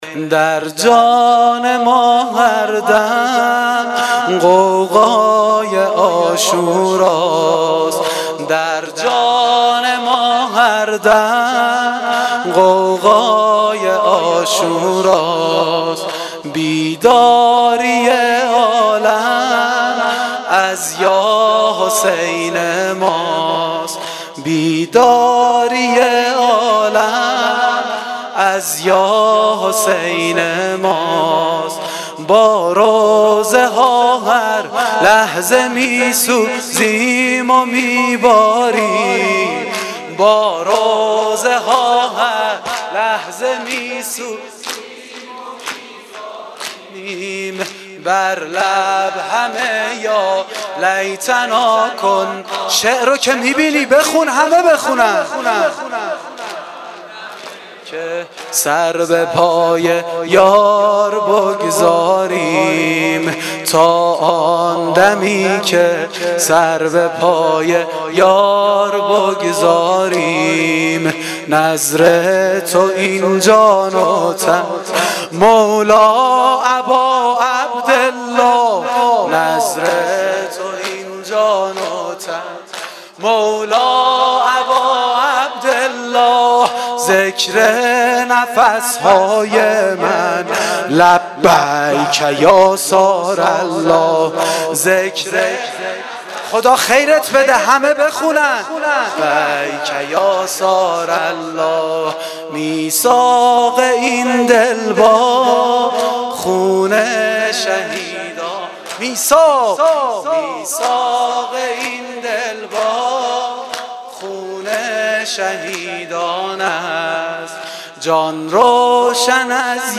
دم پایانی شب دهم محرم(عاشورای حسینی)